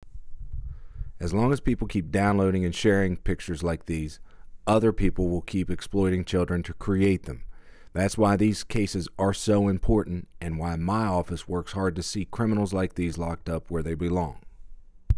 Click the links below to listen to audio sound bites from U.S. Attorney Booth Goodwin regarding today’s hearing: